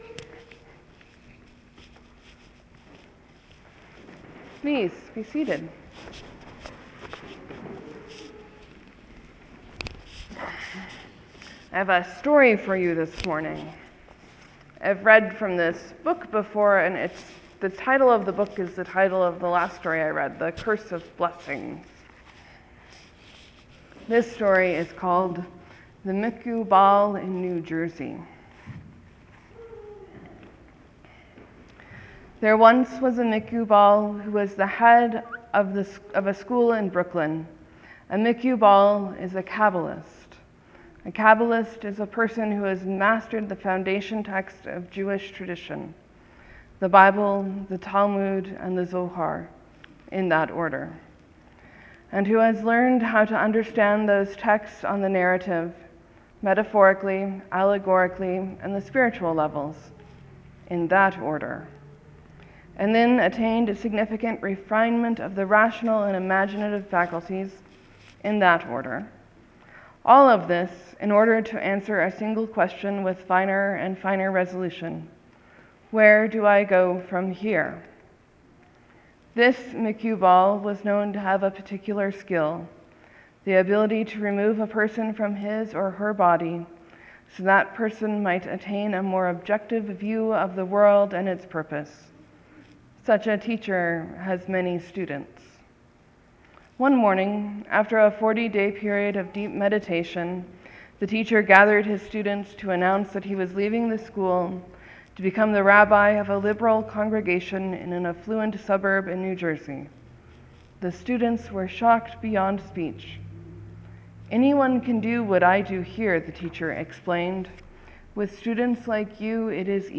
Morsels & Stories: I read from The Spy on Noah’s Ark.
So I talked about 4 different ideas that might have become a full sermon. A sort of homilectical tapas rather than a single sermon.